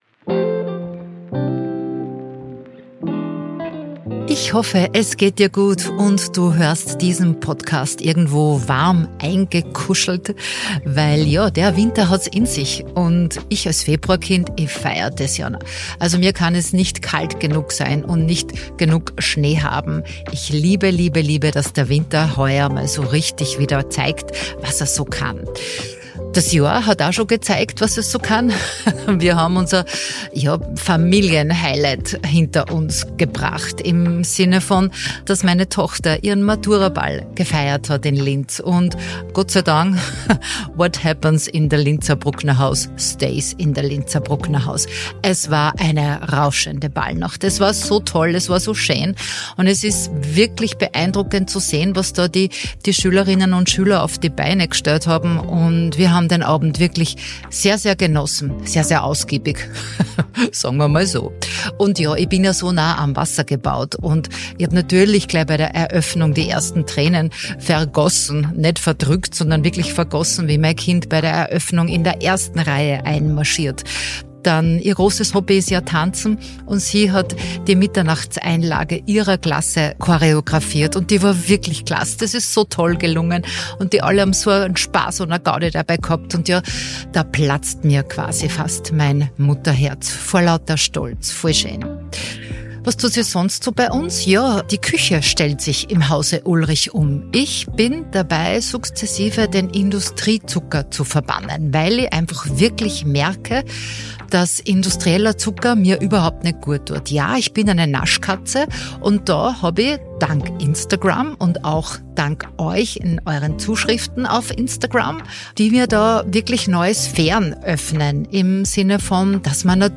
Ein ehrlicher Talk über das Älterwerden ohne Verzicht auf Lebensqualität.